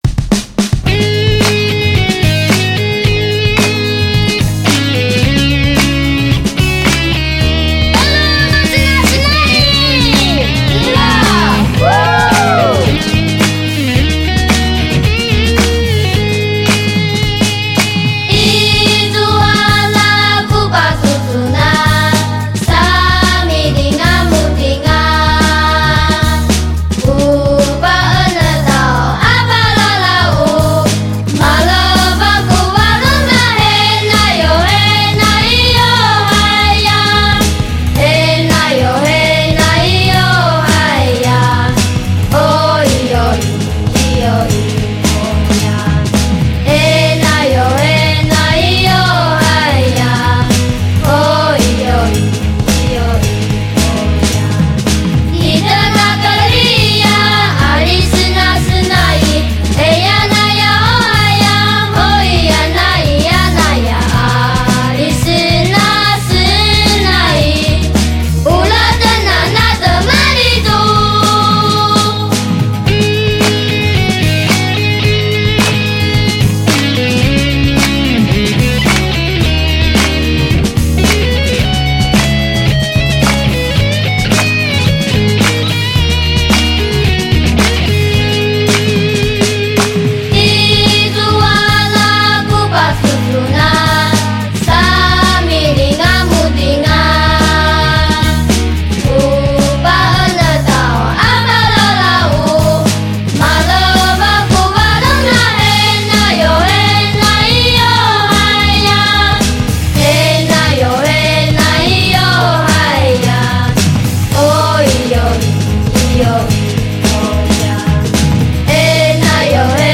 《原住民的天籟美聲》